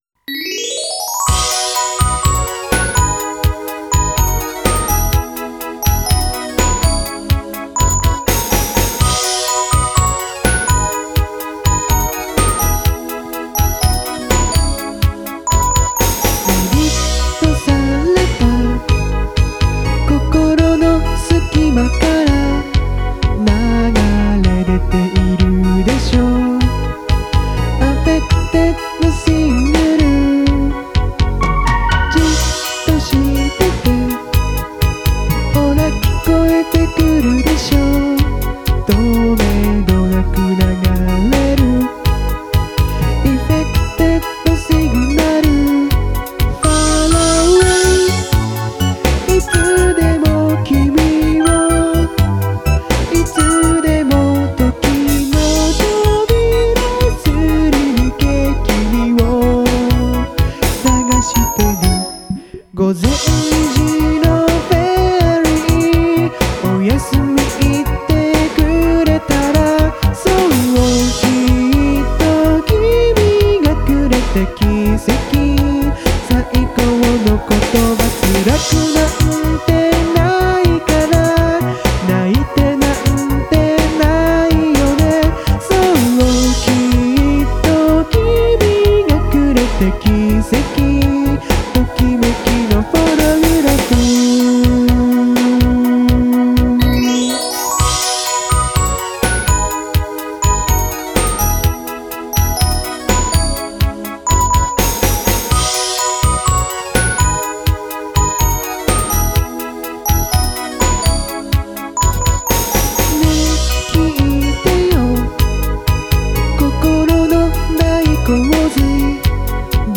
作詞・作曲・編曲・コーラス・歌：坂本総合研究所
・女の子アイドルが歌うことを想定して、メルヘンチック、乙女チックな曲
・メロディーもアレンジも、キラキラなイメージ
音源：KORG X3
MTR：YAMAHA CMX100ⅢS
エフェクター：YAMAHA EMP100
DAW(Remastering)：SONAR7 Producer Edition